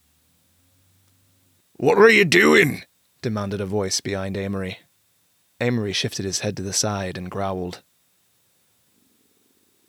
Issue with a hiss/whine in the background of some recordings
Some USB mics have a constant mosquito-like whine, but I can’t hear it on your recording. The only problem I hear is slightly excessive sibilance which can be fixed with a de-esser plugin …